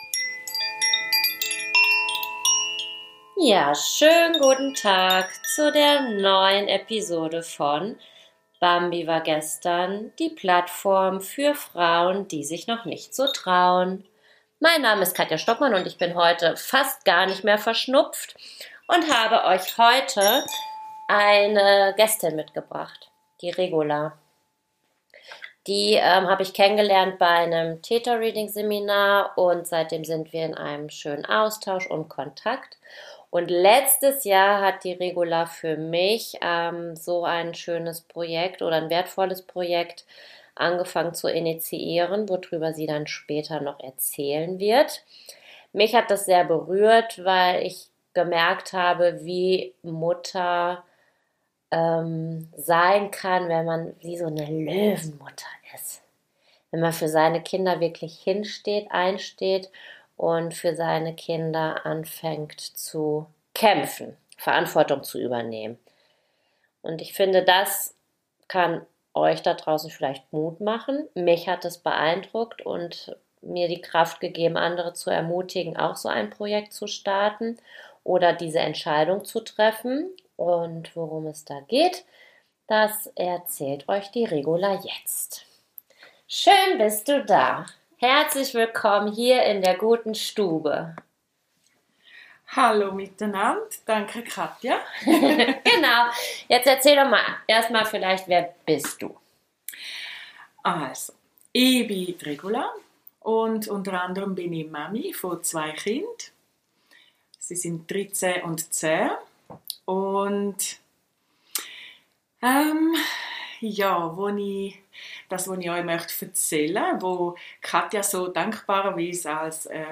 Heute habe ich als Gästin eine starke Frau, die den Druck von ihren Kindern in der Masken&Testpflichtzeit genommen hat. Sie nahm ihre Kinder aus der Schule.